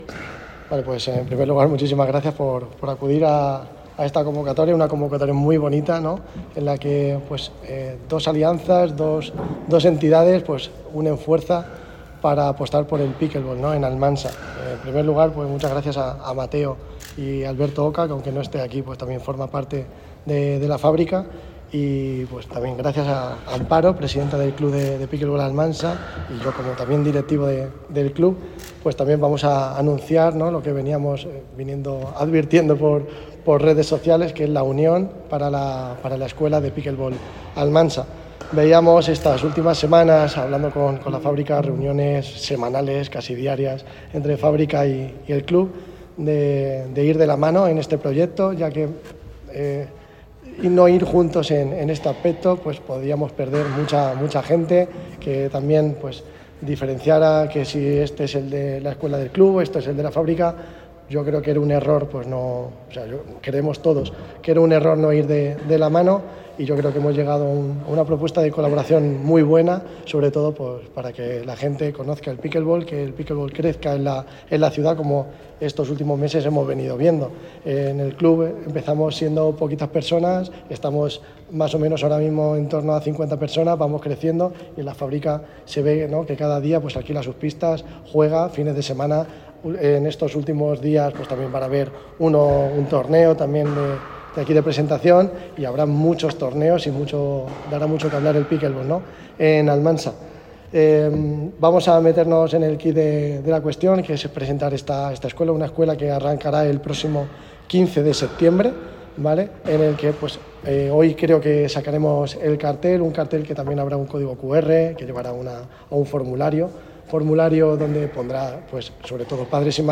En la tarde del 4 de septiembre, en las instalaciones de La Fábrica Deporte y Ocio, se presentaba de forma oficial el proyecto de la Escuela de Pickleball que se crea con la participación de la propia Fábrica y el Club Pickleball de Almansa.